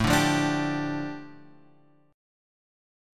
A7sus4#5 chord {5 5 3 x 3 3} chord